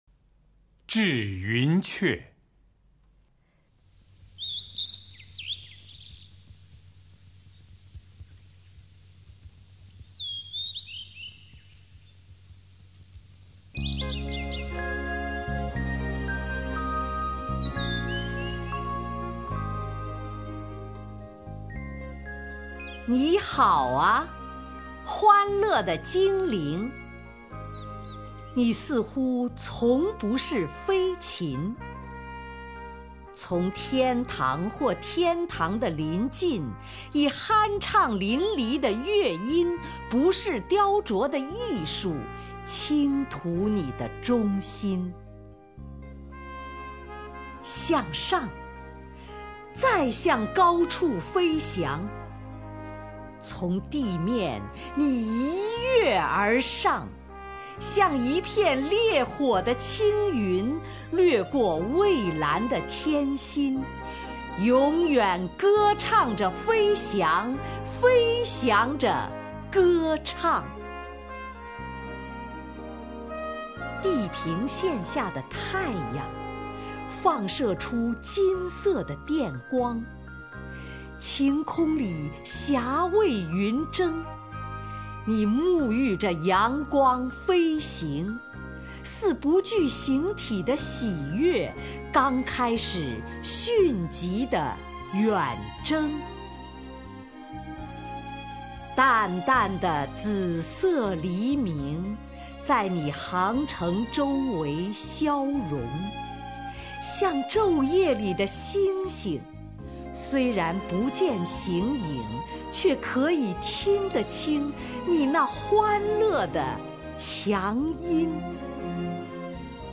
首页 视听 名家朗诵欣赏 丁建华
丁建华朗诵：《致云雀》(（英）珀西·比希·雪莱，译者：江枫)